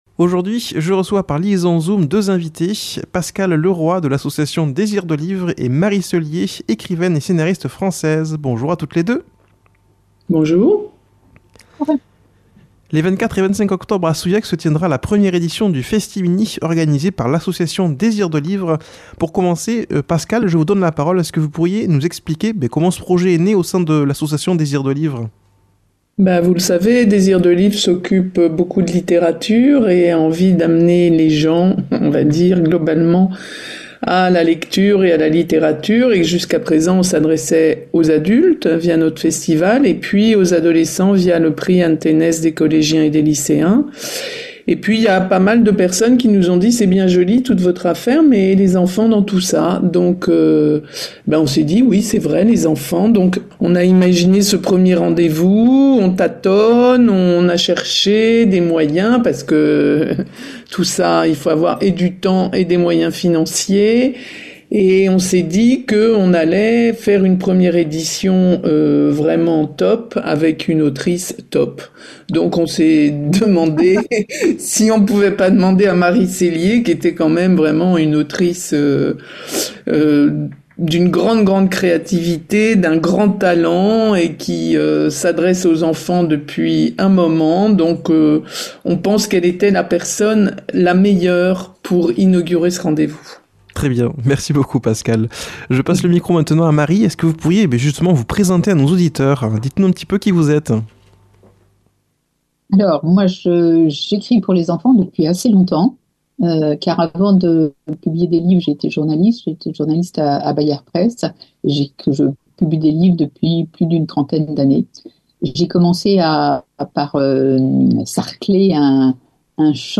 par liaison Zoom